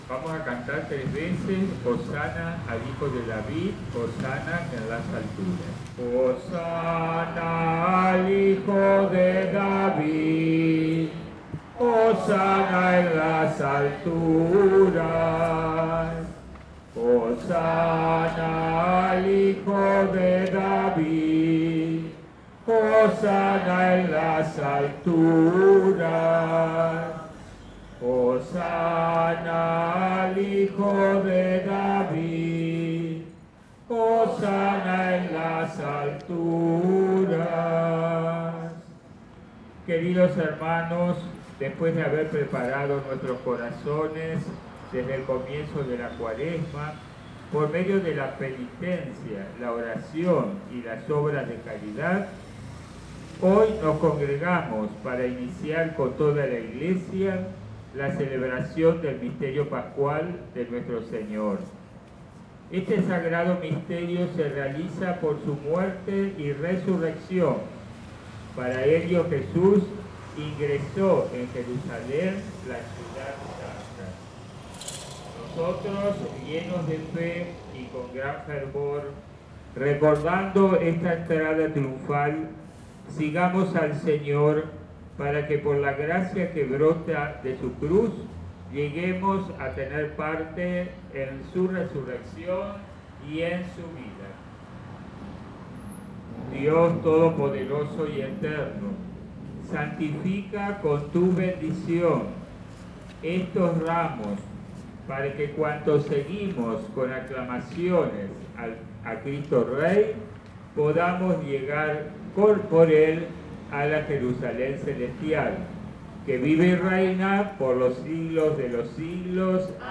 Parroquia Nuestra Señora de las Nieves - Sonidos de Rosario
Domingo de ramos Buenos Aires 4801 Ciudad de Rosario 19 hs. 02 de Abril 2023
psr-parroquia-nuestra-senora-de-las-nieves-domingo-de-ramos.mp3